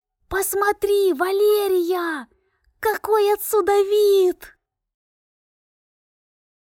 Озвучка текста для аудиокниги